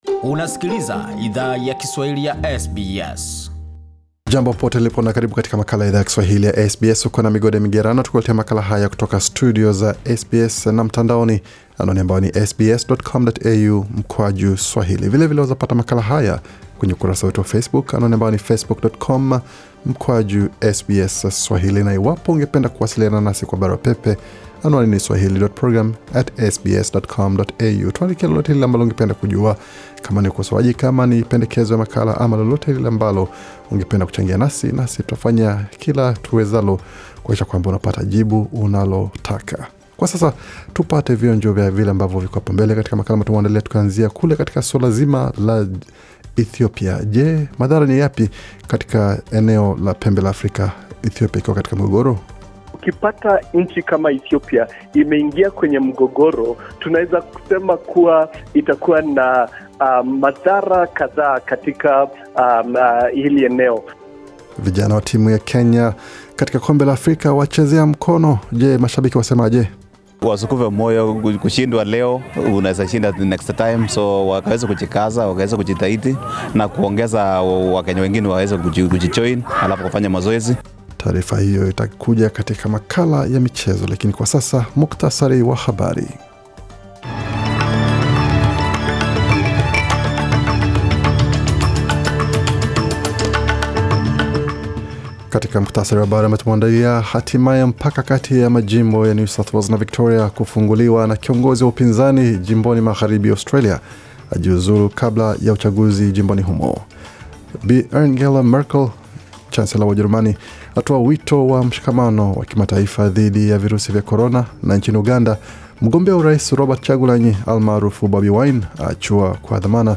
Taarifa ya habari 22 Novemba 2020